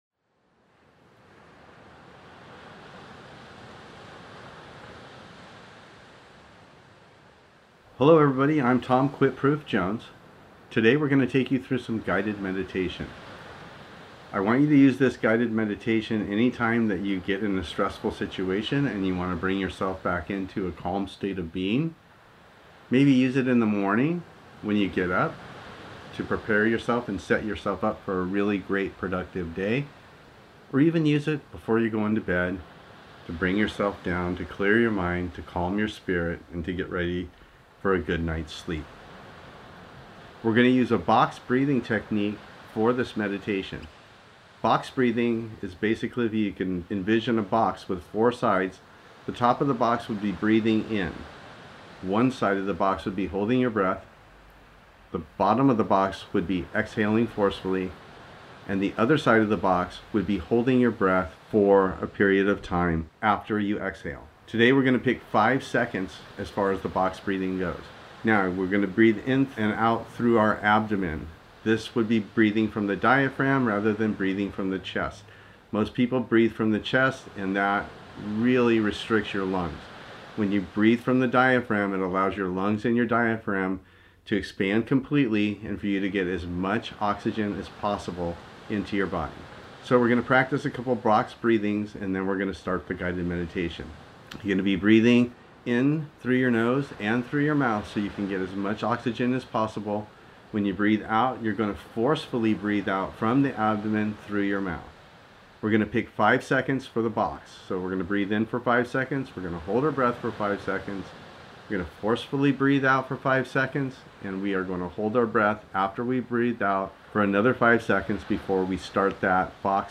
Free Audio Meditation